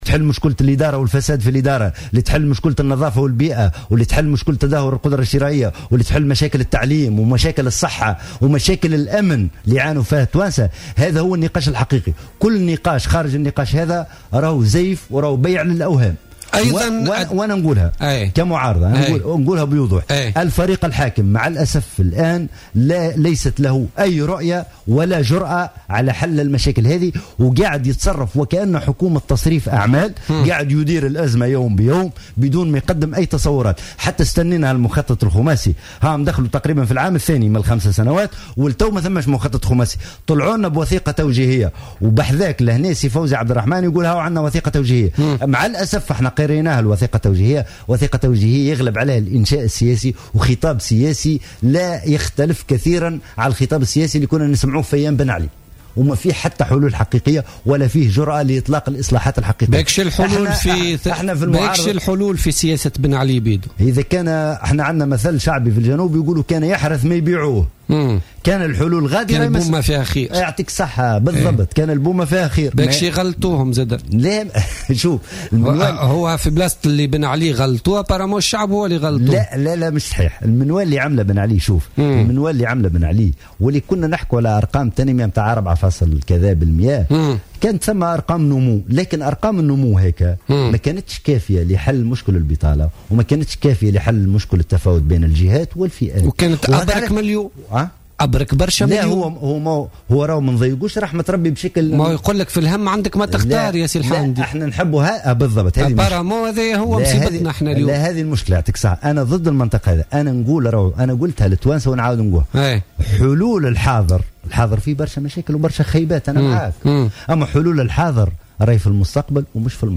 وقال الحامدي في مداخل له اليوم الثلاثاء في برنامج "بوليتيكا" : الفريق الحاكم مع الأسف يتصرف وكأنه حكومة تصريف أعمال ويدير الأزمة يوما بيوم دون تقديم أي تصورات."